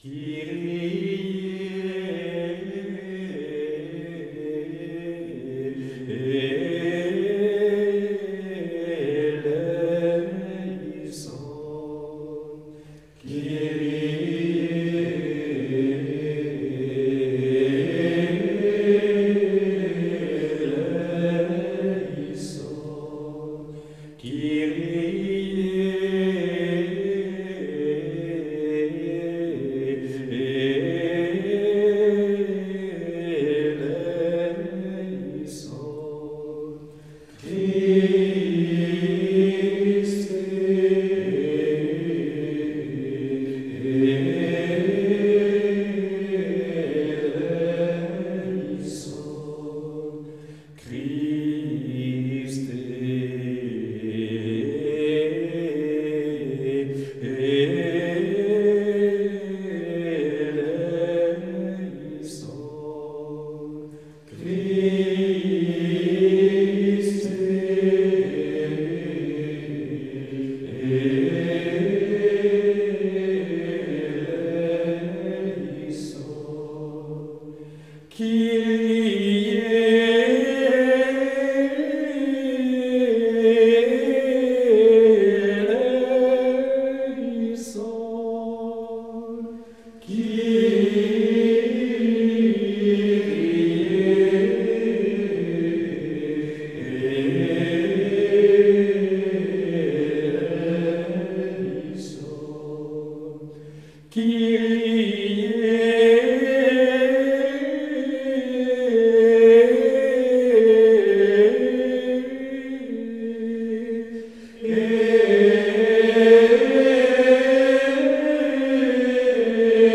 Ce Kyrie est daté du Xe siècle et sa mélodie est assez développée. Il s’agit d’un 7e mode, qui monte progressivement et déploie peu à peu son bel enthousiasme.
La mélodie s’attarde longuement dans les graves, plutôt en 8e mode qu’en 7e, et plus précisément entre le Sol et le Do, respectivement fondamentale et dominante du 8e mode, avec des appuis sur le Fa, sous-tonique du mode de Sol.
Le second Kyrie est encore plus sobre, plus grave, plus piano.
Jusqu’ici, ce Kyrie est demeuré extrêmement réservé, ne s’élevant vraiment, mais de façon restreinte que sur chacun des eléison.
Le 7e mode jaillit enfin, brusquement, sur le premier des trois derniers Kyrie.
Enfin, le dernier Kyrie reprend la formule à l’aigu, de façon identique, se pose sur le Ré, puis renouvelle en crescendo la même mélodie très aérienne, avant de redescendre finalement vers le grave en reprenant la formule de l’avant-dernier Kyrie, aboutissant à la cadence finale de eléison.
Ce Kyrie du mode de Sol est un peu mystérieux dans sa première partie, très joyeux dans sa seconde partie, très ferme dans son ensemble.